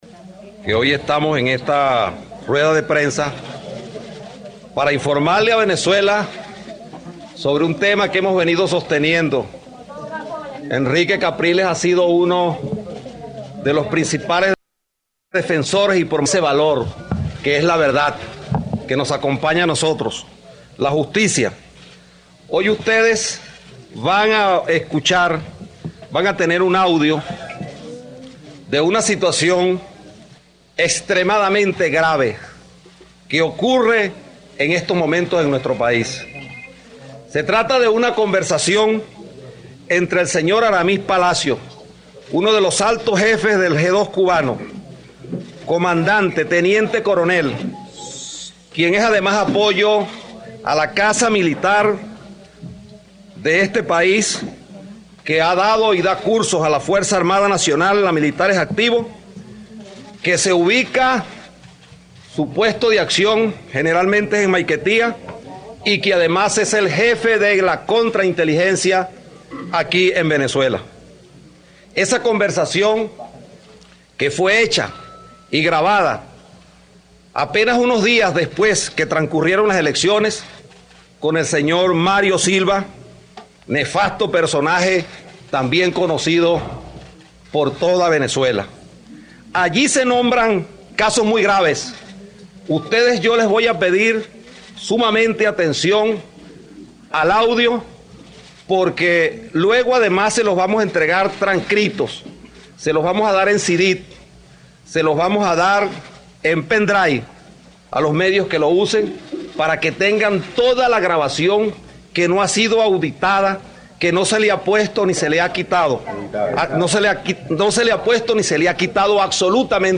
Ismael García y grabación de conversación entre Mario Silva y agente del G2 cubano